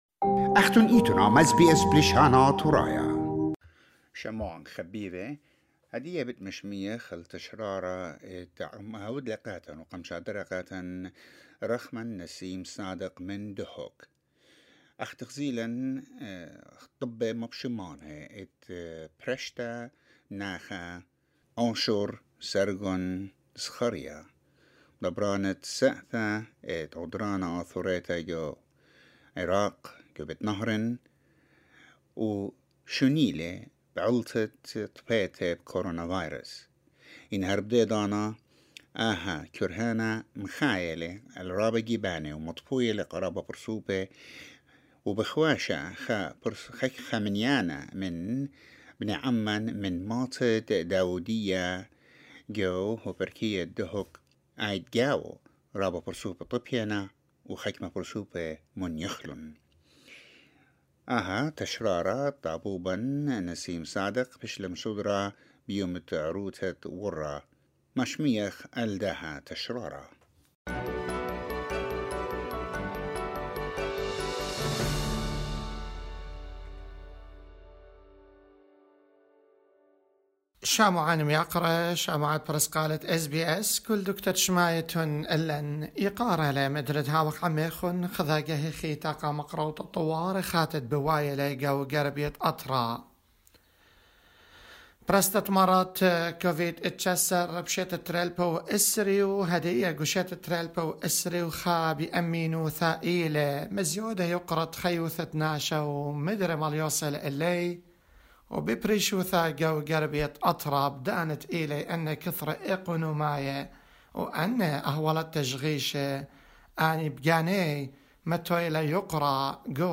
SBS Assyrian